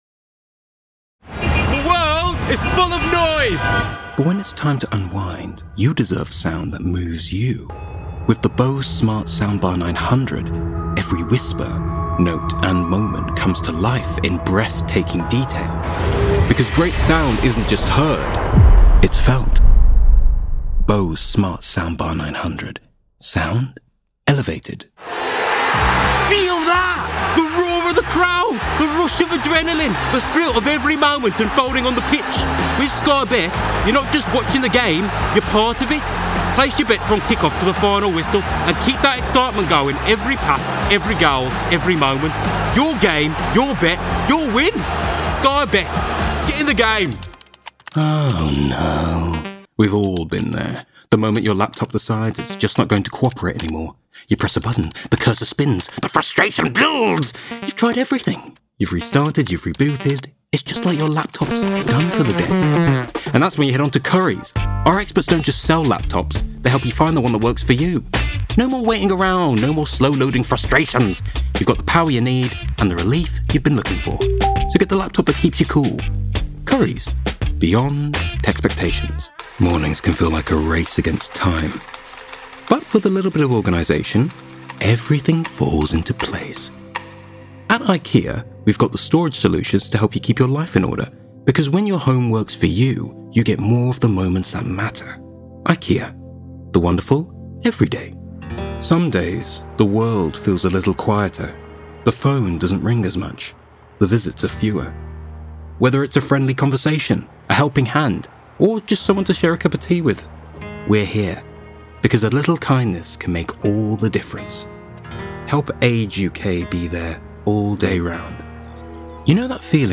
Commercial Showreel
Male
West Midlands
Bright
Friendly
Playful